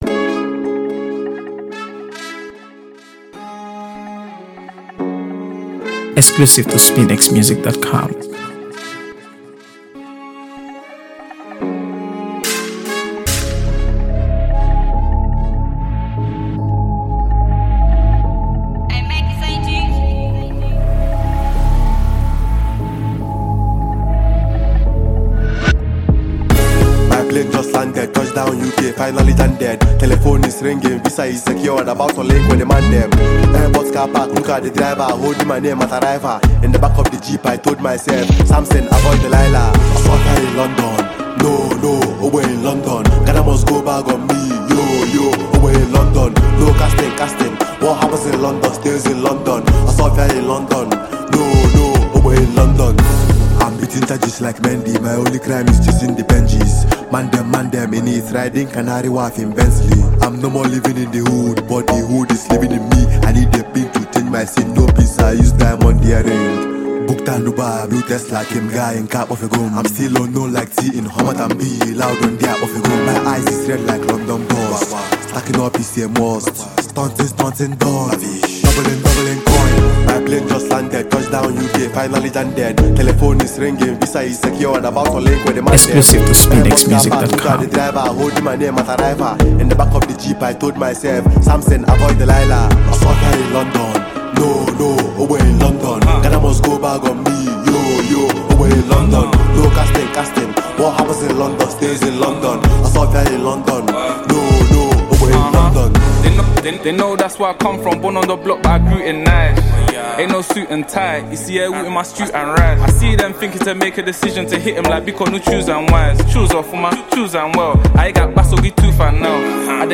AfroBeats | AfroBeats songs
With its contagious rhythms and appealing melodies